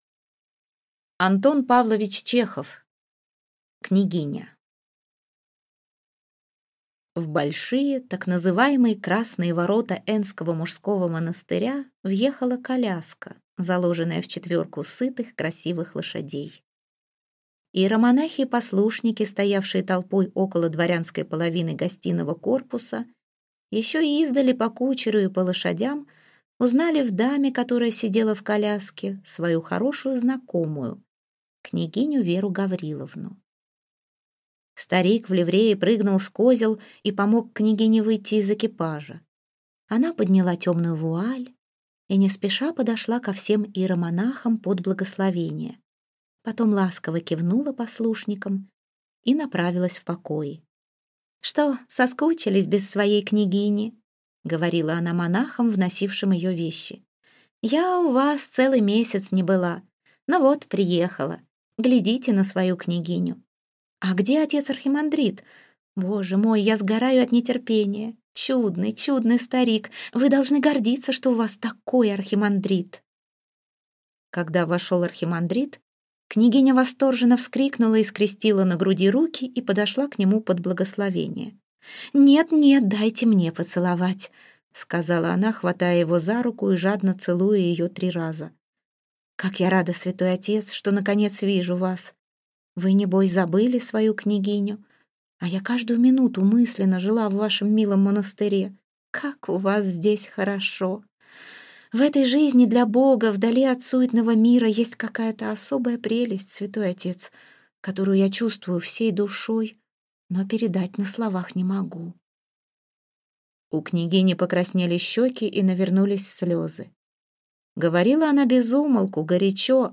Аудиокнига Княгиня